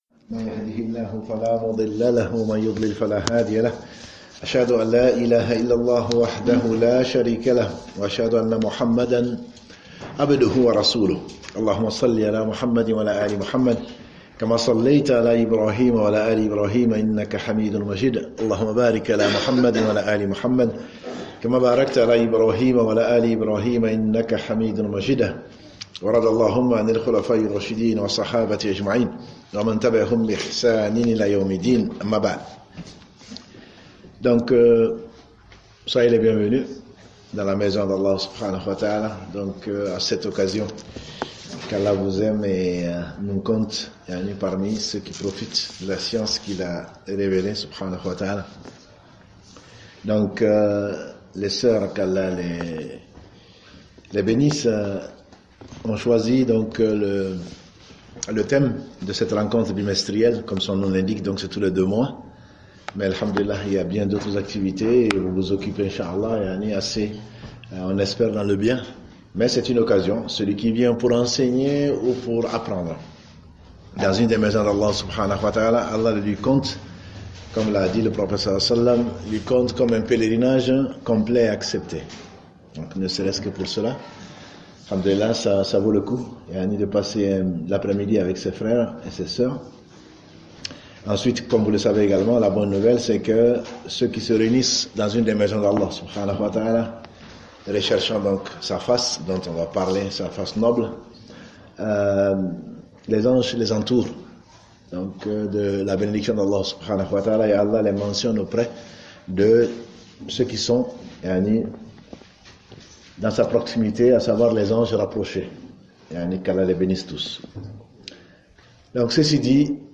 Djoumu'a du 24/03/2019